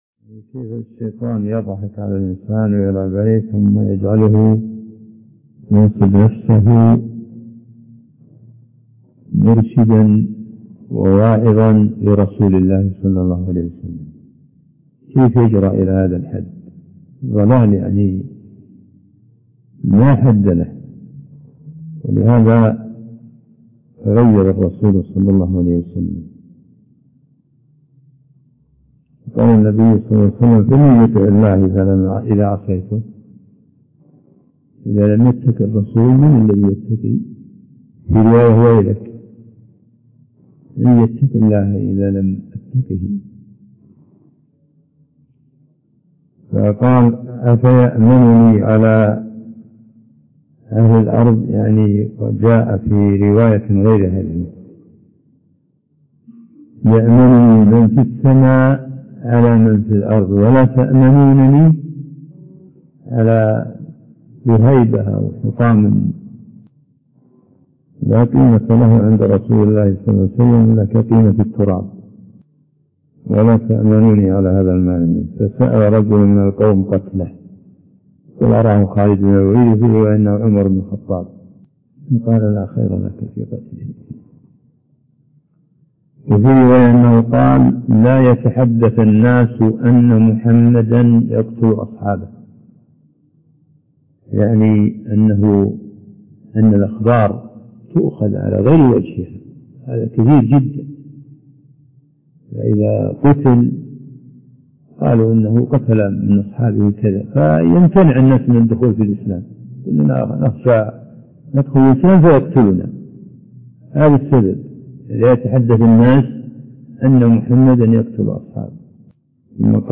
عنوان المادة الدرس (6) شرح كتاب التوحيد من صحيح البخاري تاريخ التحميل الأحد 29 يناير 2023 مـ حجم المادة 43.43 ميجا بايت عدد الزيارات 358 زيارة عدد مرات الحفظ 94 مرة إستماع المادة حفظ المادة اضف تعليقك أرسل لصديق